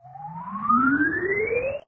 object_grow_2.ogg